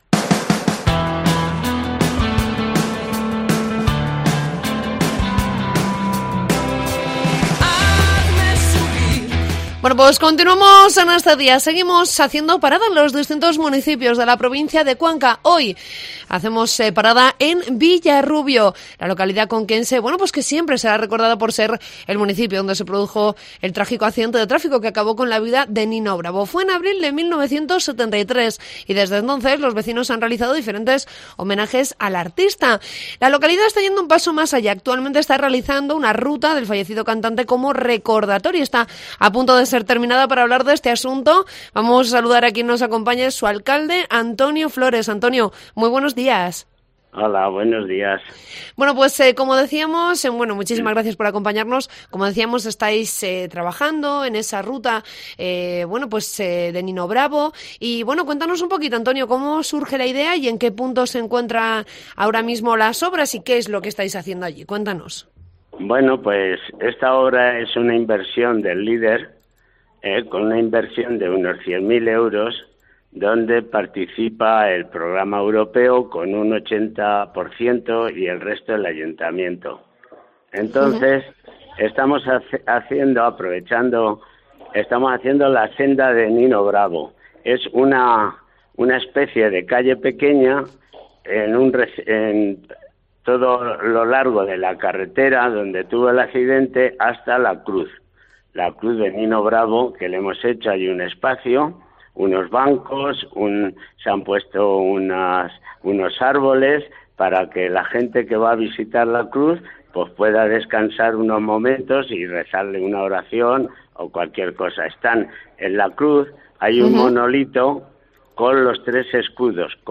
Entrevista con el alcalde de Villarrubio, Antonio Flores